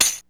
Rhythm Machine Sound "DRUMTRAKS"
tamb.wav